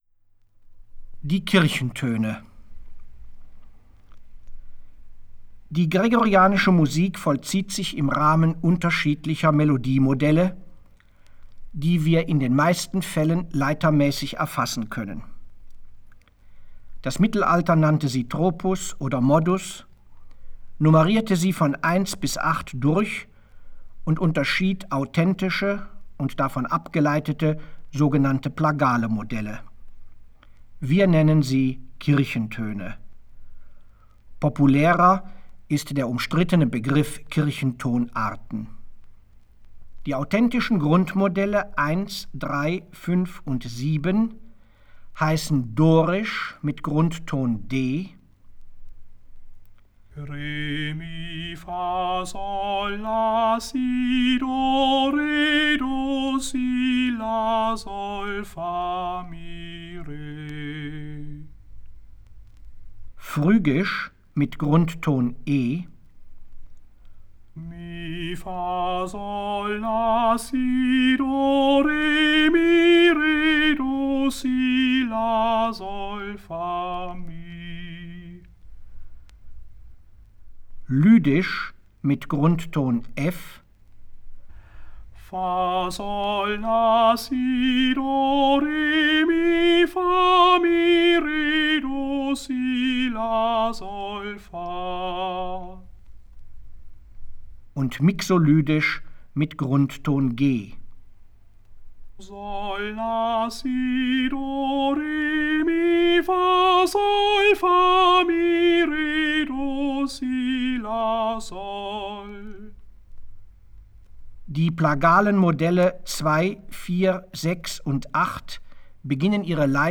Schola gregoriana im Auftrag der Robert-Schumann-Hochschule Düsseldorf,